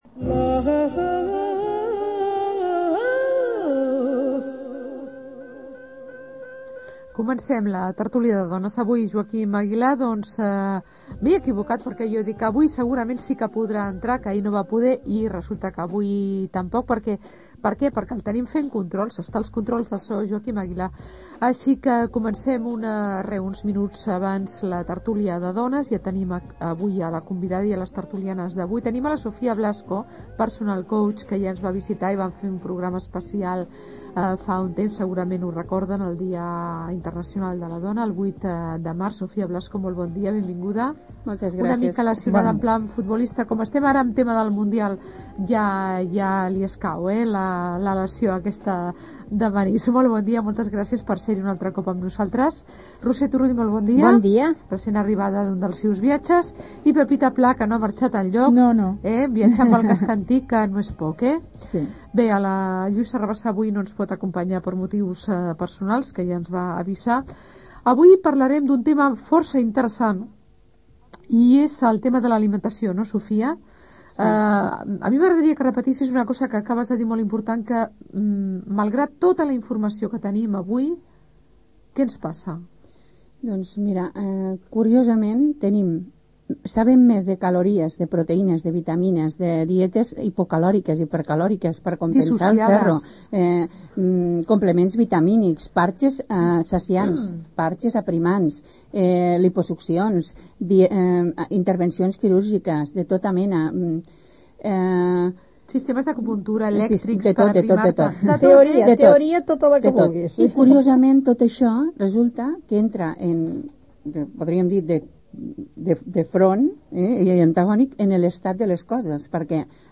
Al programa ‘Tertúlia de dones’ de Ràdio Premià de Mar, parlant d’alimentació i salut.